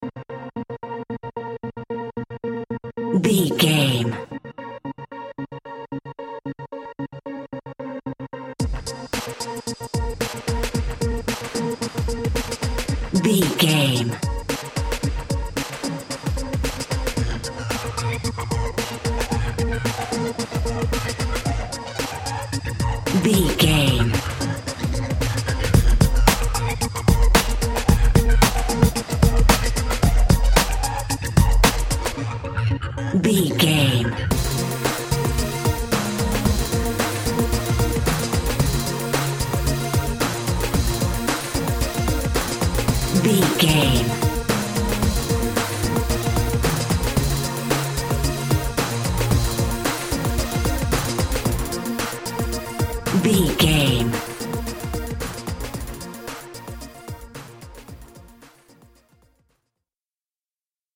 Hip Hop Rappers Battle.
Aeolian/Minor
B♭
electronic
synth lead
synth bass